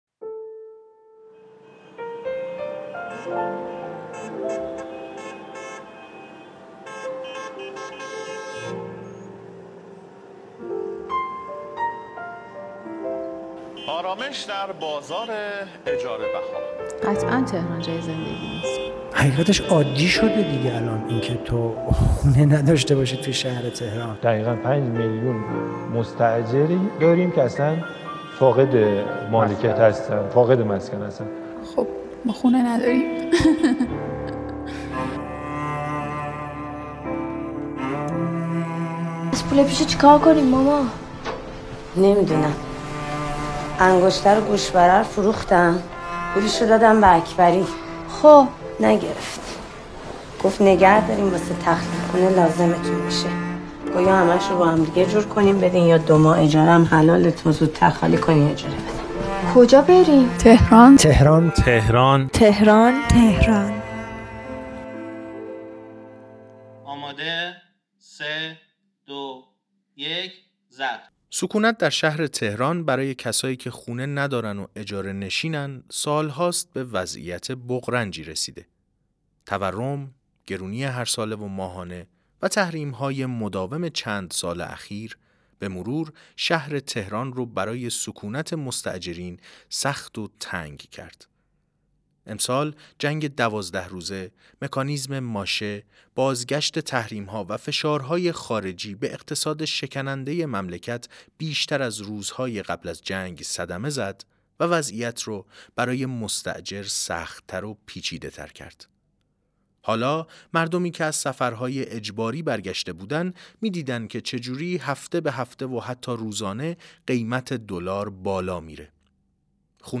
در قسمت ششم این پادکست در سه بخش به موضوعات اجاره‌نشینی، آلودگی و ترافیک می‌پردازیم. در این بخش، صدای مردمی را می‌شنویم که درباره تجربه زیستن در شرایط اجاره‌نشینی به ما می‌گویند.